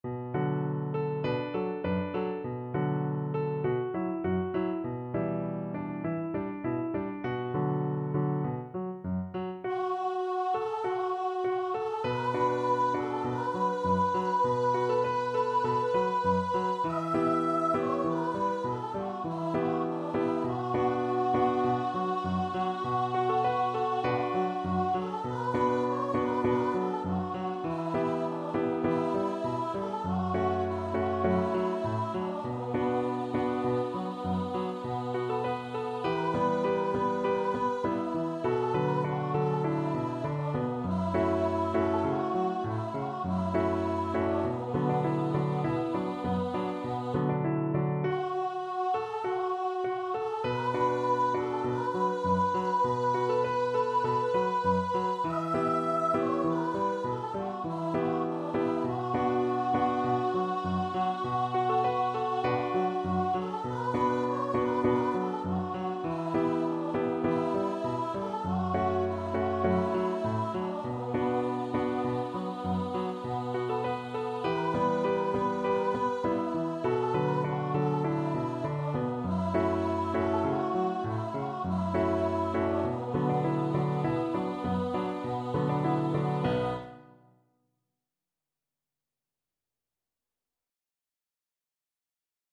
Voice version
Traditional Music of unknown author.
Moderato
B4-E6
2/4 (View more 2/4 Music)
Classical (View more Classical Voice Music)